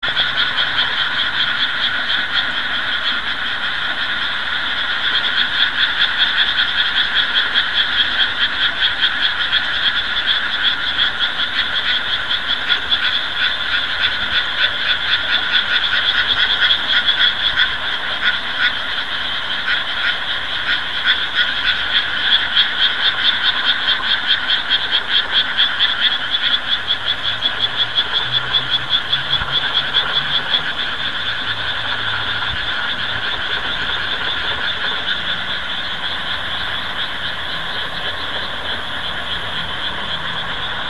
裏の田んぼ
今年もカエルたちの合唱を楽しませていただいてます。
裏の田んぼ に 今年も無事水が入りました。